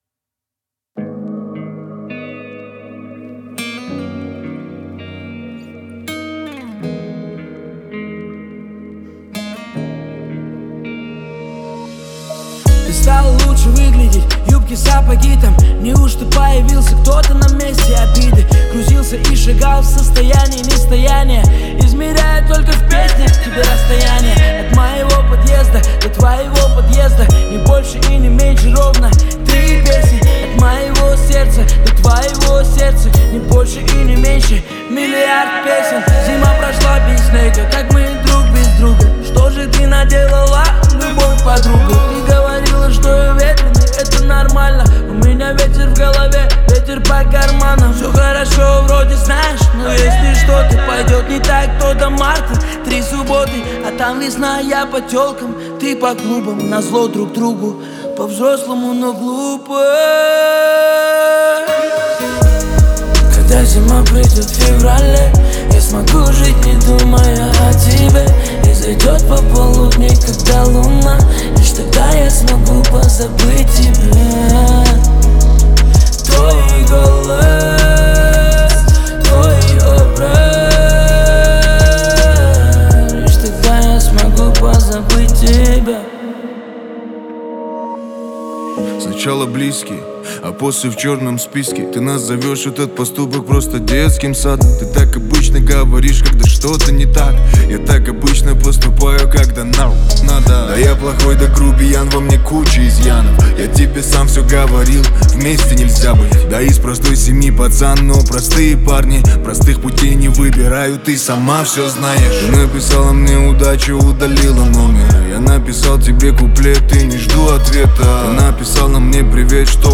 это трек в жанре хип-хоп с элементами R&B